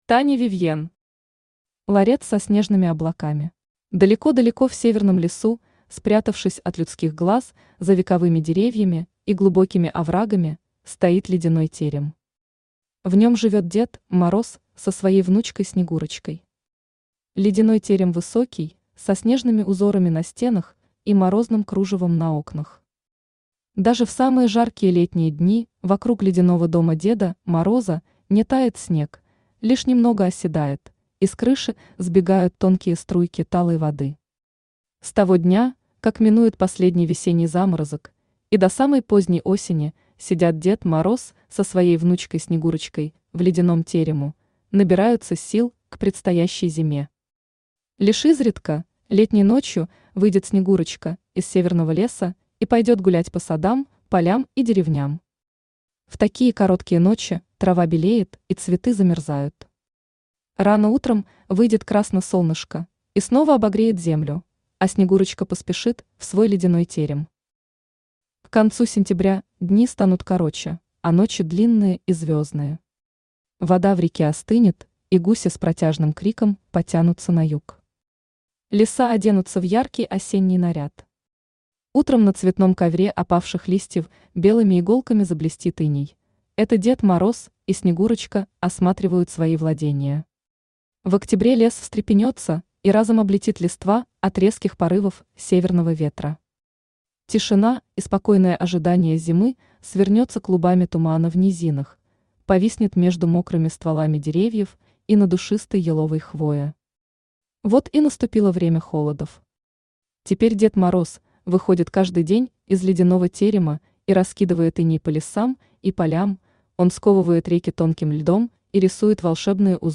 Аудиокнига Ларец со снежными облаками | Библиотека аудиокниг
Aудиокнига Ларец со снежными облаками Автор Таня Вивьен Читает аудиокнигу Авточтец ЛитРес.